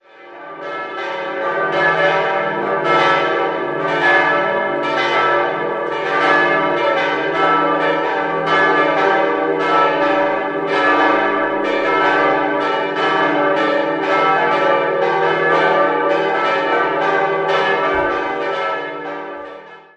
4-stimmiges Gloria-TeDeum-Geläute: d'-e'-g'-a'